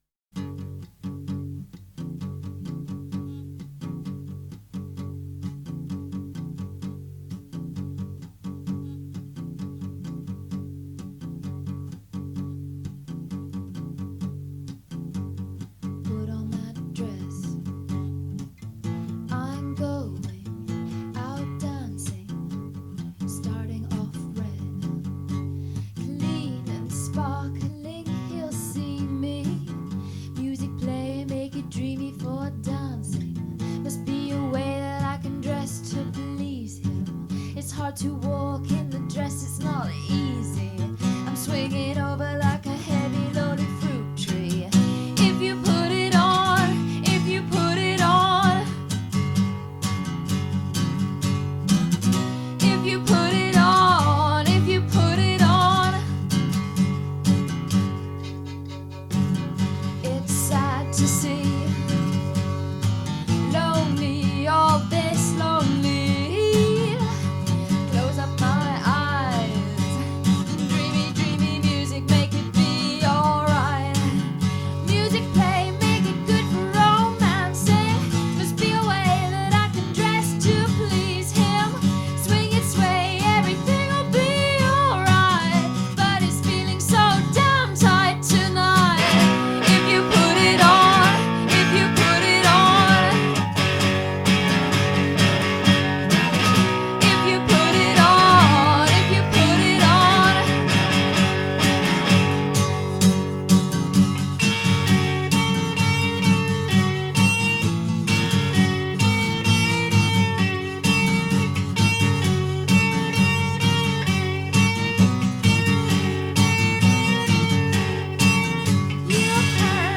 an acoustic guitar for company